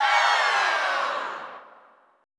Index of /90_sSampleCDs/Best Service - Extended Classical Choir/Partition I/AHH FALLS
AHH HI SLW-R.wav